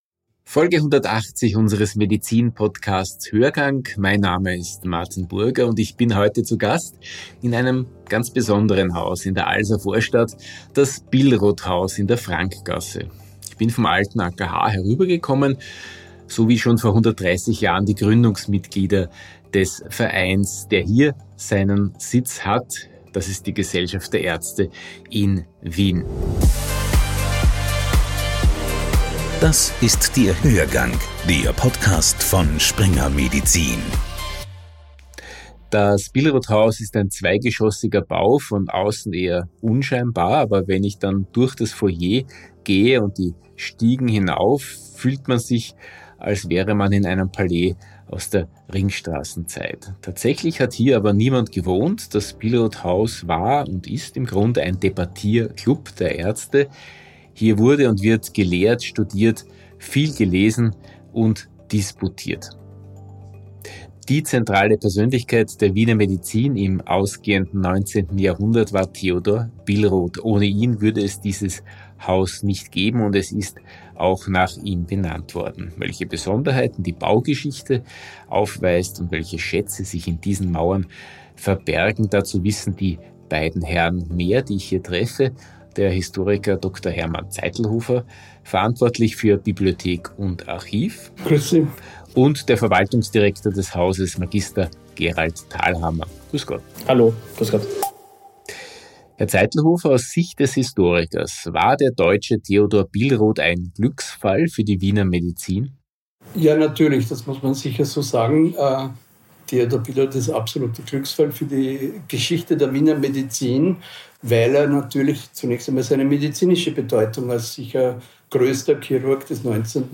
Heute bin ich zu Gast in einem Haus in der Alservorstadt, dem Billrothhaus.
Dann gehe ich durch das Foyer, und es fühlt sich an, als wäre ich in einem Palais der Ringstraßen-Epoche. Tatsächlich hat hier aber niemand gewohnt, das Billrothhaus war ein Debattierclub der Ärzte-Elite.